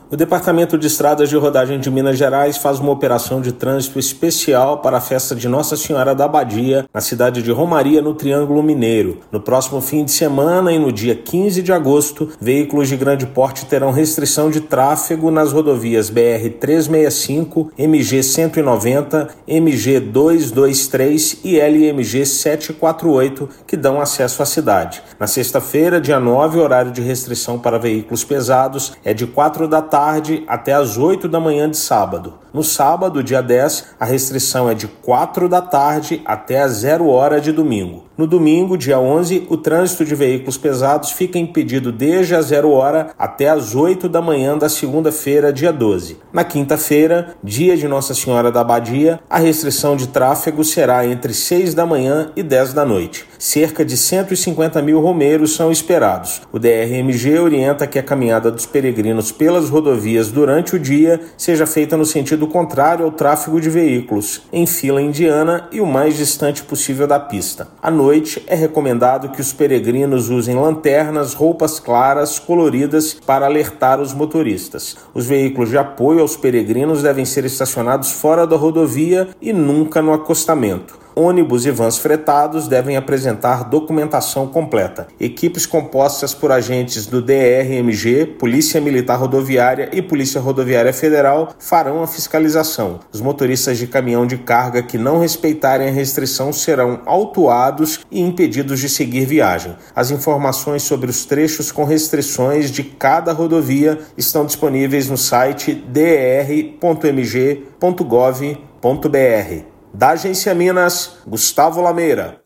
Medida será adotada em quatro rodovias que dão acesso ao município de Romaria. Ouça matéria de rádio.